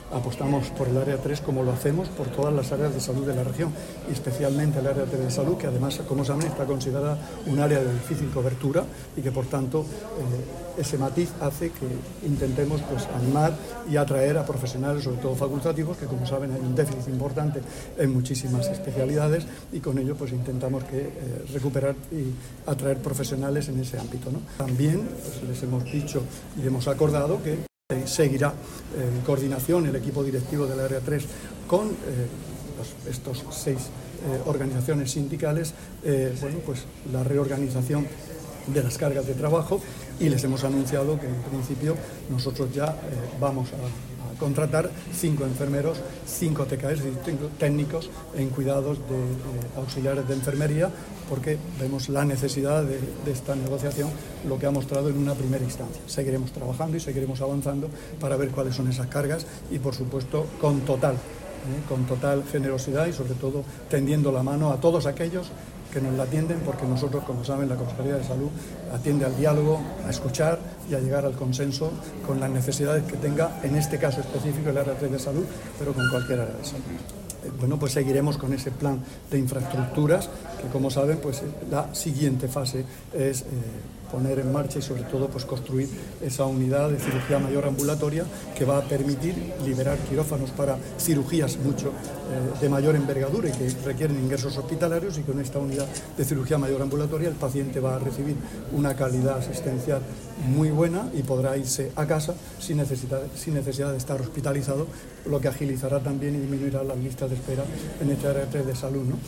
Sonido/ Declaraciones del consejero de Salud, Juan José Pedreño, sobre la creación de nuevas plazas para profesionales en el Área III de Salud.